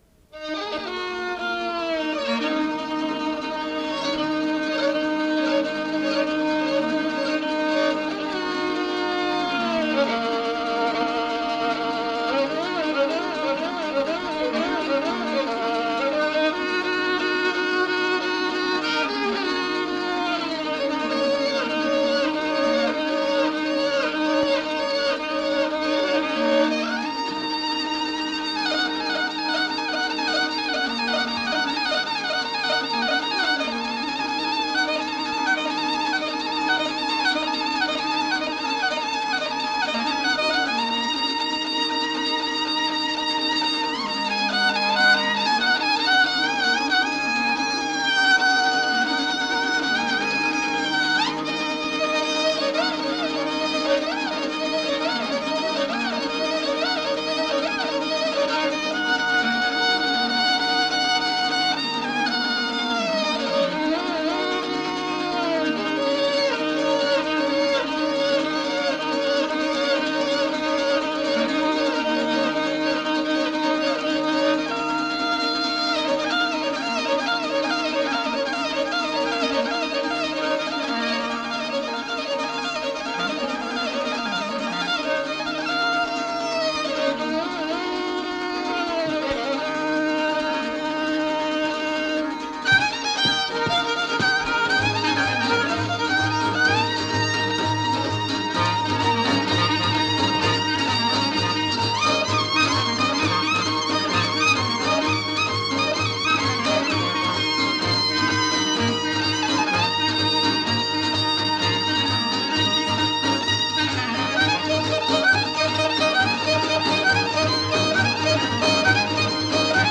Kaba with two parts: kaba and dance. The first part of the kaba is structured and developed as a labiko type kaba. The second part is elaborated on a dance of Central Albania which does not refer to the polyphonic format but to the one-voice one.
11.-Kaba-me-violine-sazet-e-koop-bujq-Baltoskote-Fier.wav